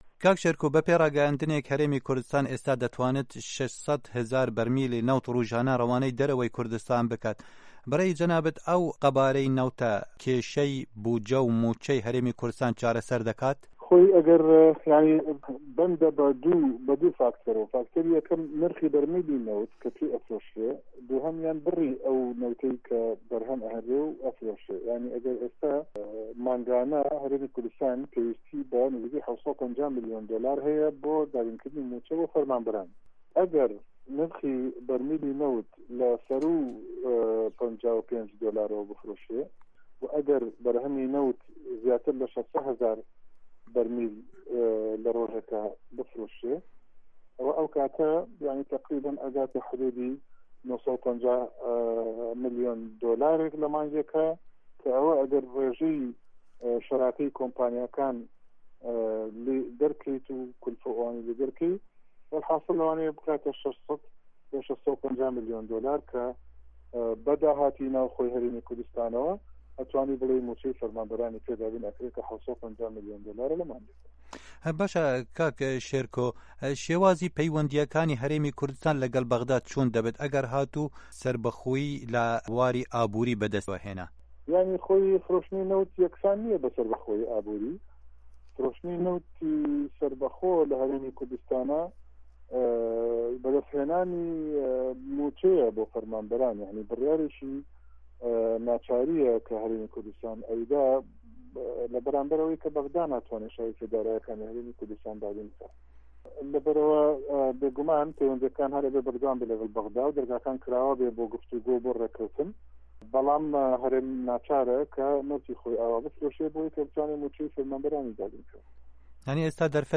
Şêrko Cewdet, endamê parlamena Herêma Kurdistanê, di hevpeyvînekê de ligel Dengê Amerîka got, eger bihayê her birmîleke petrolê 55 dolar be, pirsa aborî ya hikûmeta Herêma Kurdistanê dê çareser bibe.
Hevpeyvin digel Şêrko Cewdet